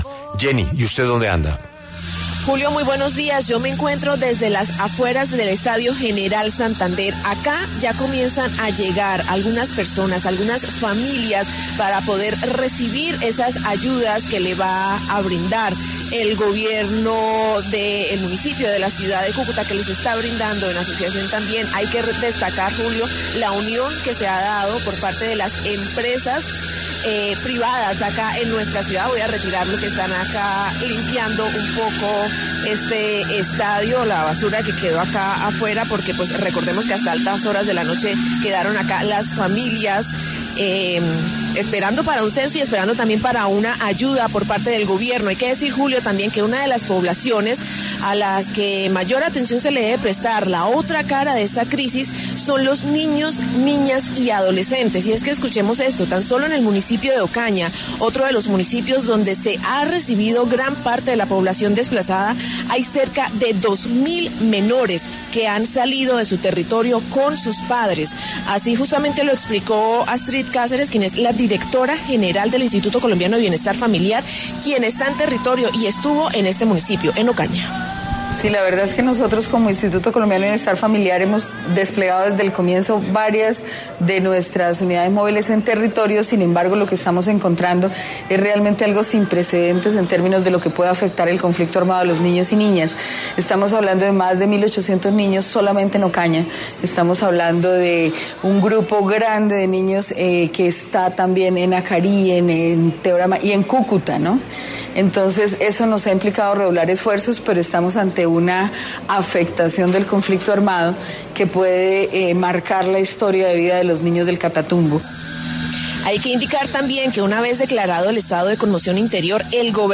W Radio sigue haciendo presencia en el Estadio General de Santander, en Cúcuta, donde se acumulan miles y miles de habitantes del Catatumbo que fueron desplazados por cuenta de la crisis humanitaria que se presenta tras los enfrentamientos entre el ELN y disidencias Farc.
En diálogo con La W, una mujer contó que en dos ocasiones ha sido desplazada de esta región.